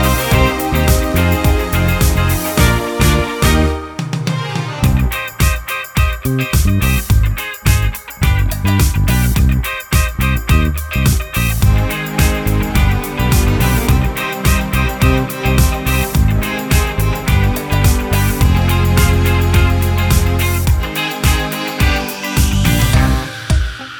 Duet Version Pop (2010s) 3:04 Buy £1.50